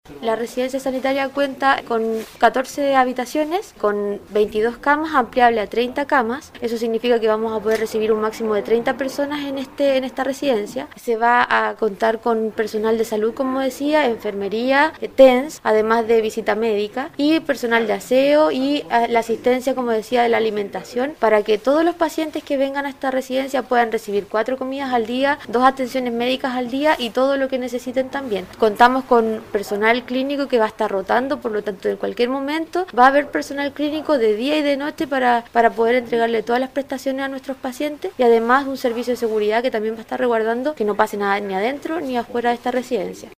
Fernanda Matamala, jefa de la Autoridad Sanitaria en la provincia de Chiloé, indicó que esta Residencia viene a culminar un proceso que se ha realizado en todo el país conocido como TTA, es decir Testeo, Trazabilidad  y Aislamiento.